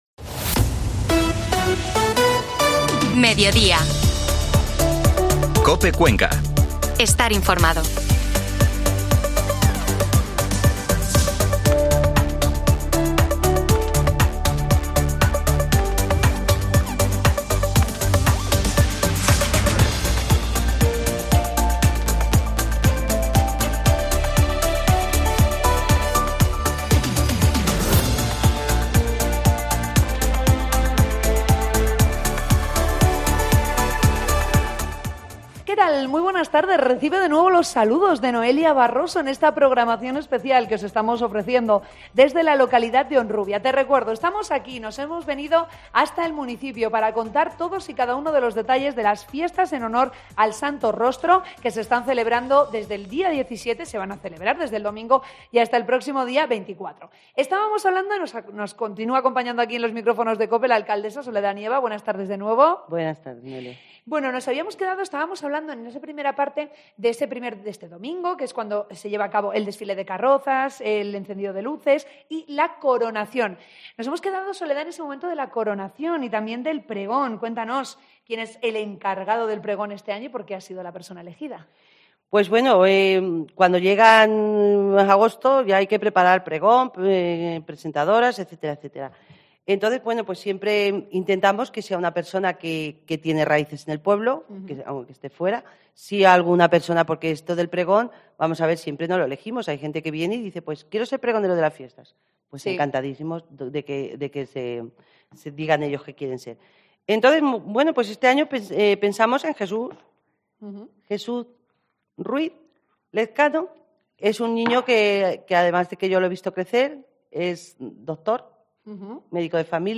AUDIO: Escucha Mediodía COPE desde Horubia con motivo de las fiestas patronales en honor al Santo Rostro